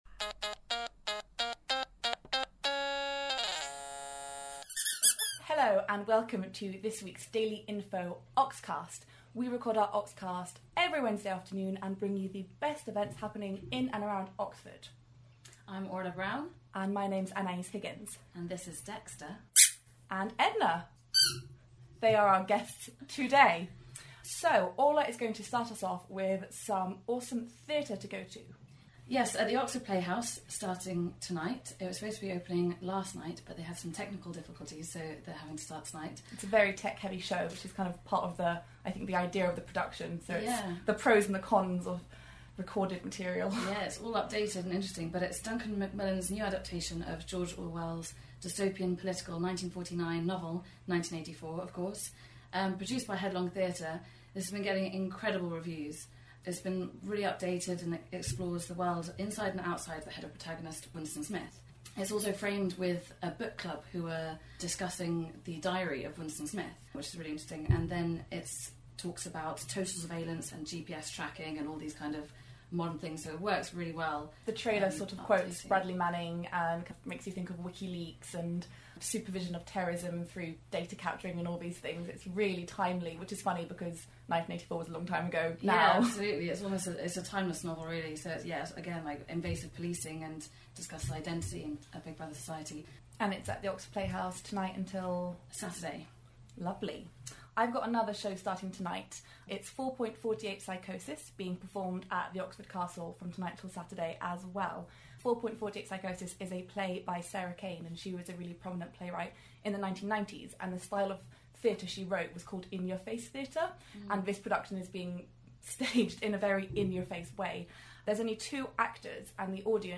The Daily Info team discuss this weeks best events - don't miss important issue-based theatre, Oxford's own bake Off and Banbury Canal Day.